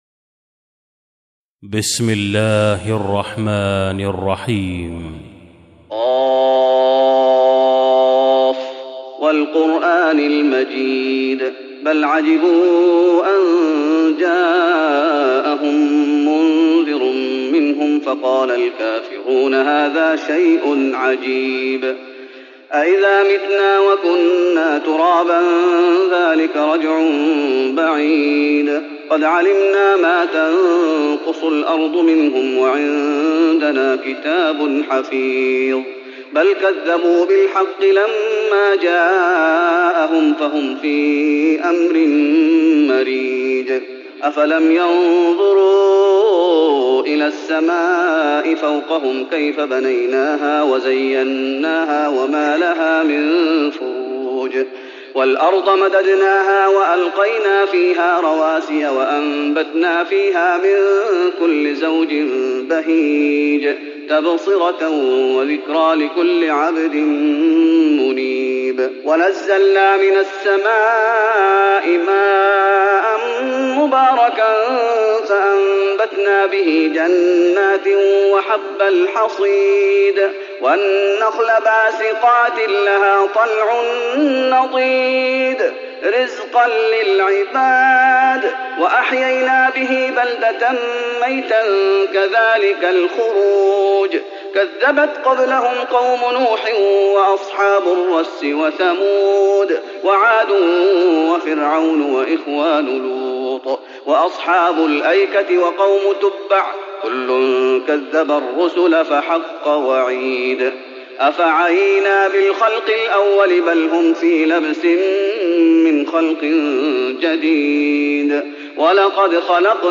تراويح رمضان 1415هـ من سورة ق Taraweeh Ramadan 1415H from Surah Qaaf > تراويح الشيخ محمد أيوب بالنبوي 1415 🕌 > التراويح - تلاوات الحرمين